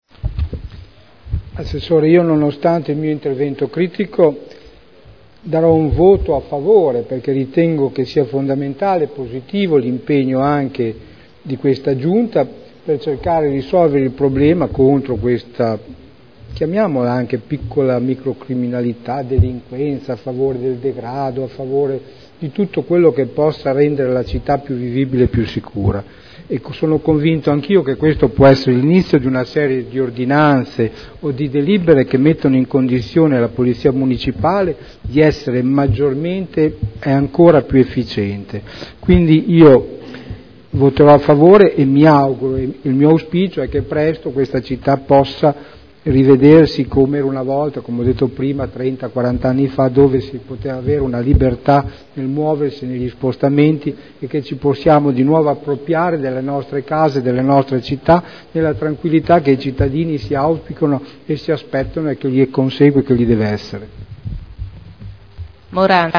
Seduta del 20/06/2011. Modifiche al Regolamento di Polizia Urbana approvato con deliberazione del Consiglio comunale n. 13 dell’11.2.2002 Dichiarazioni di voto